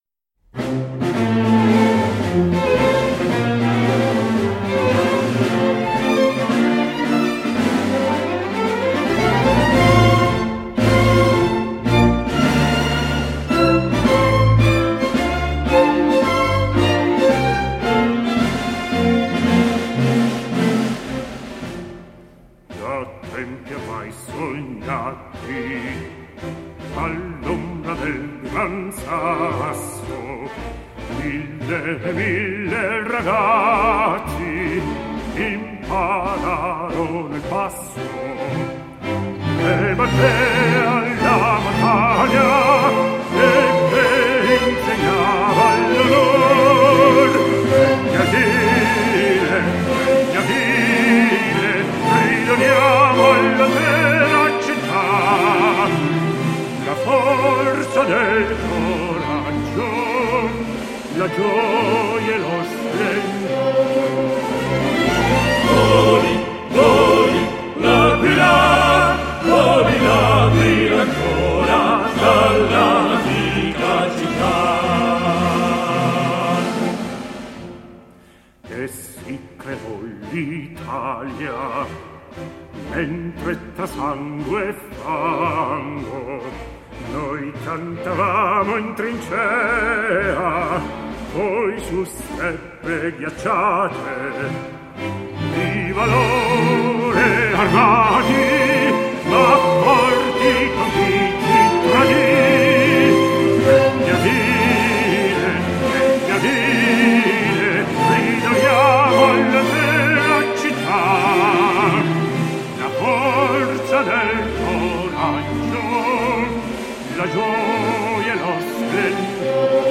L’inno dell’88ª Adunata